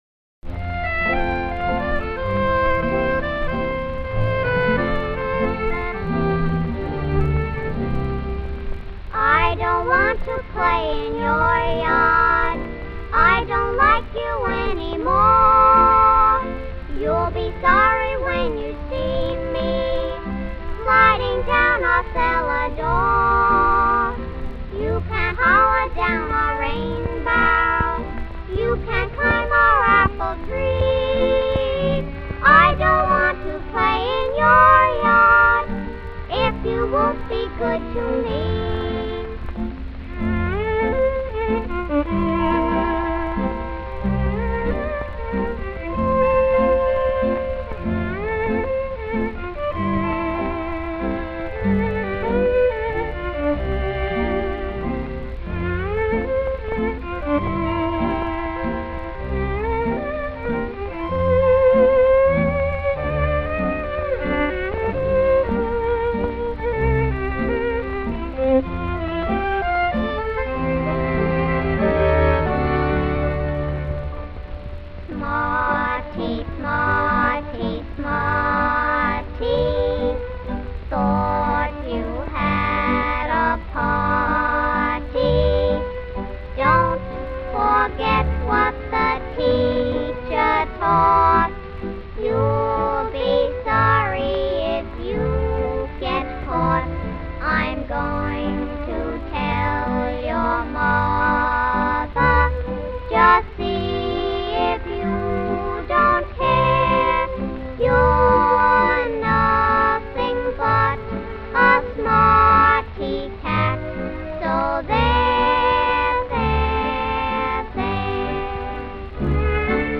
smack of old-school vaudeville